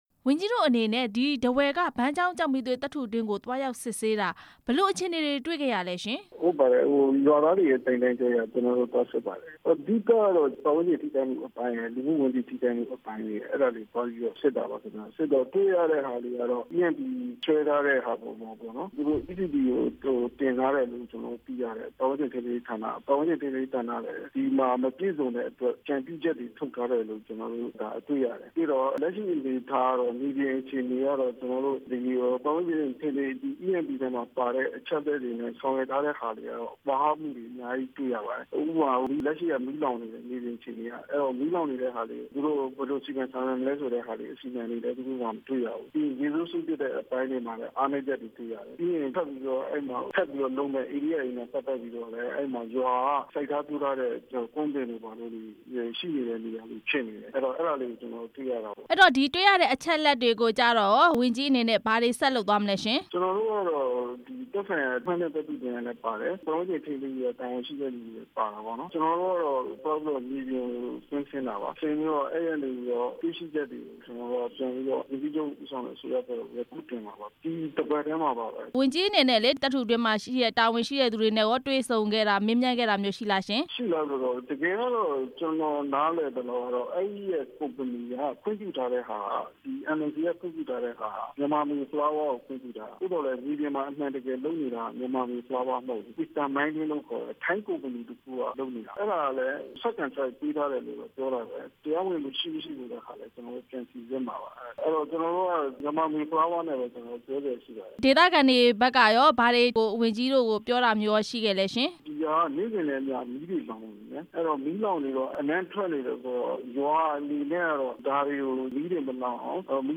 ဘန်းချောင်း ကျောက်မီးသွေး စီမံကိန်း အခြေအနေ မေးမြန်းချက်